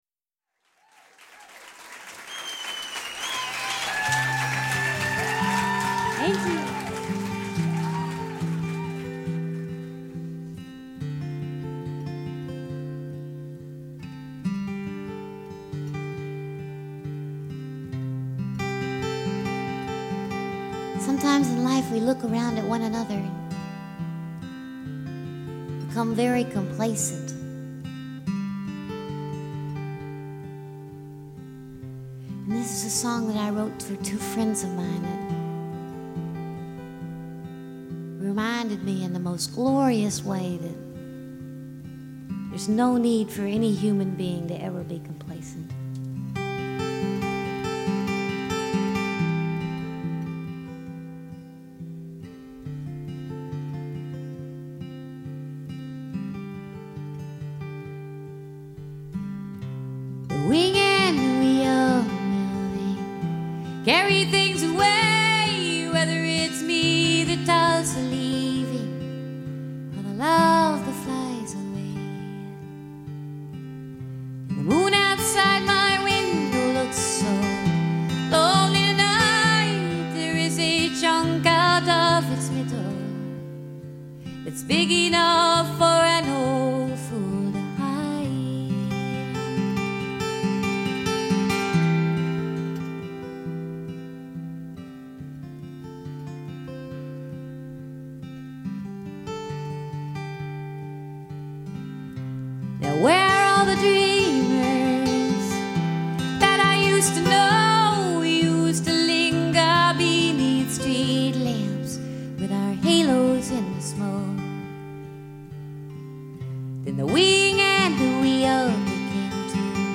Sweet-voiced songstress